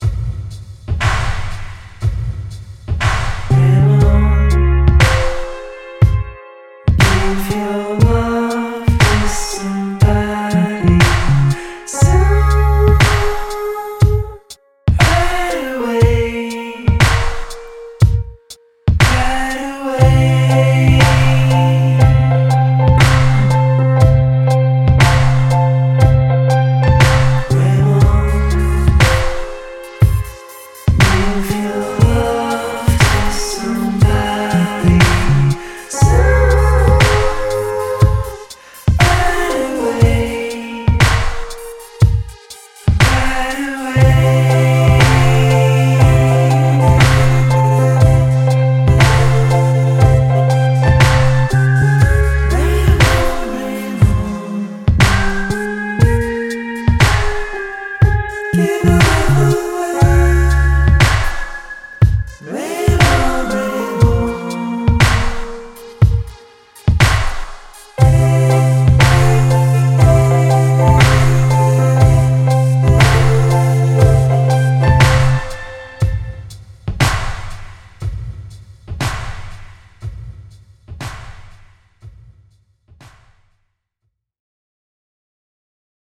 in a modern R&B incarnation.